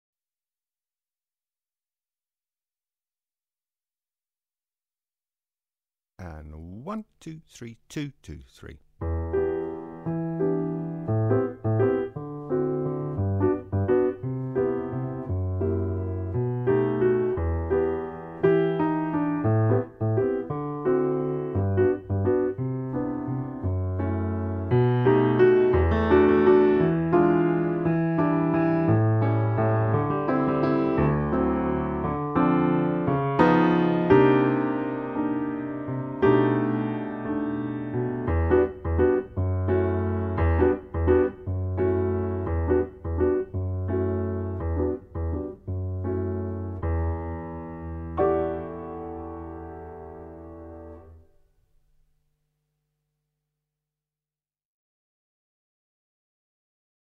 Instrument: flute and piano
Grade: very easy—easy
Sample Backing Track